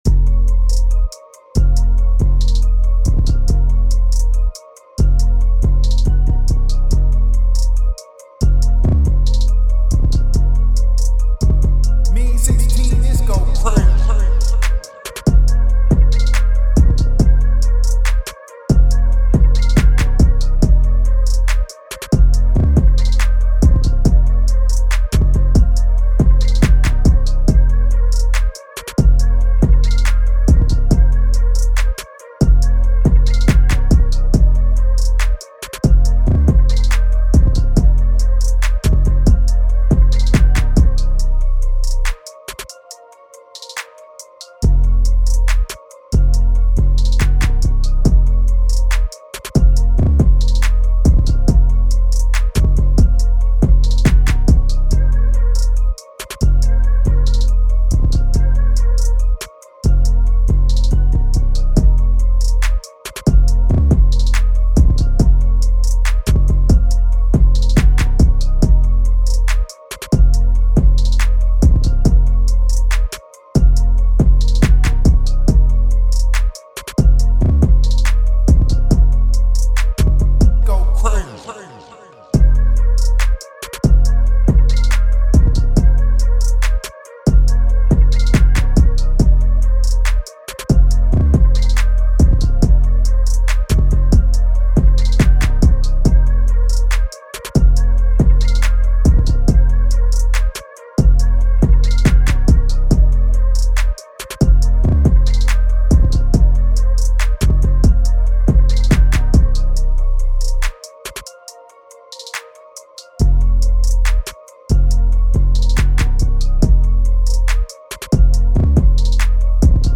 TRAP
A#-Min 140-BPM